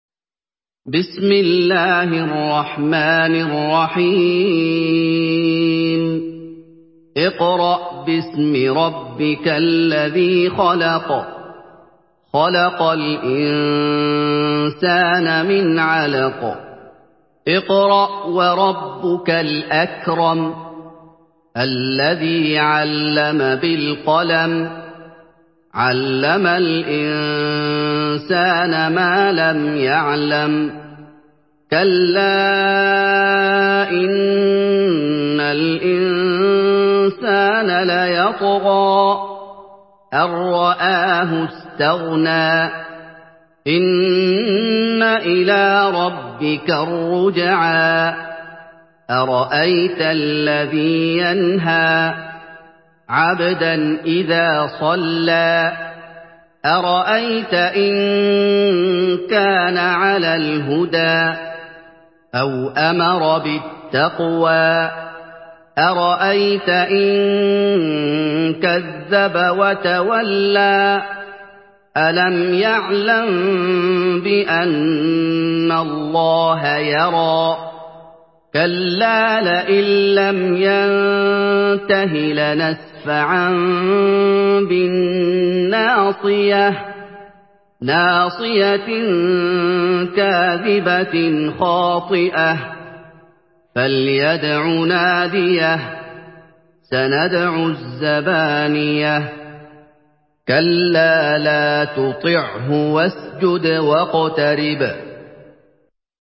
Surah Al-Alaq MP3 in the Voice of Muhammad Ayoub in Hafs Narration
Murattal